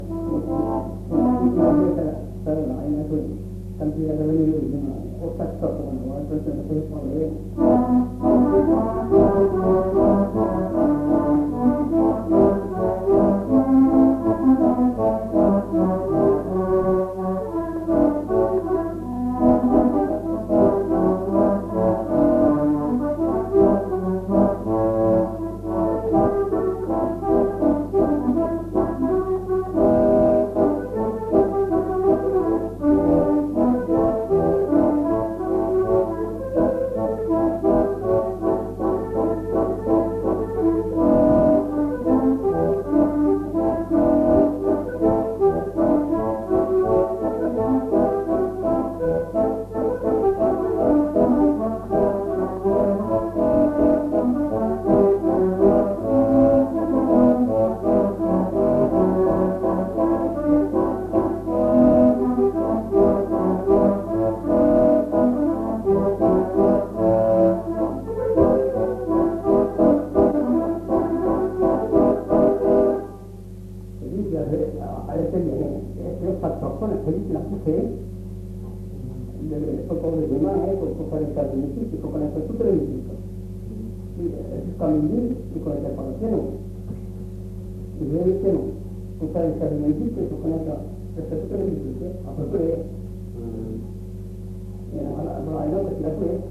Aire culturelle : Marmandais gascon
Lieu : Escassefort
Genre : morceau instrumental
Instrument de musique : accordéon diatonique
Danse : marche (danse)